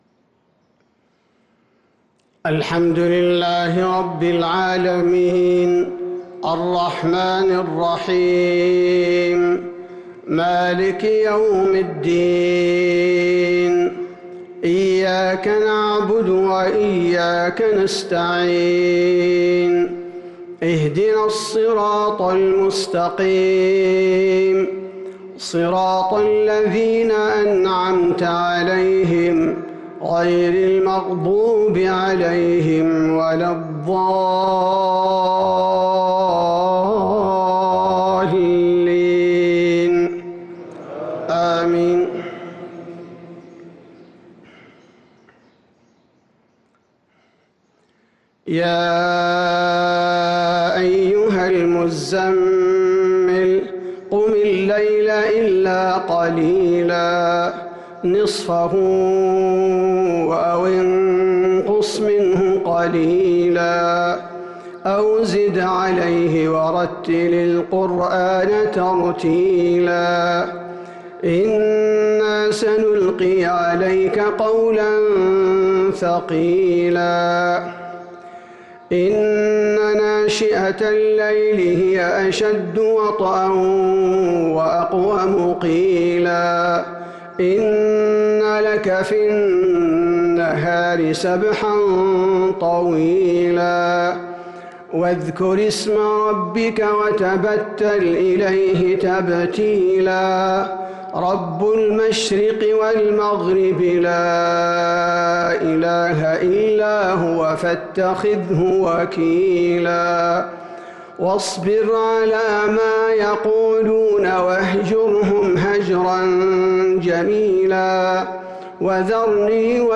صلاة الفجر للقارئ عبدالباري الثبيتي 1 رمضان 1443 هـ
تِلَاوَات الْحَرَمَيْن .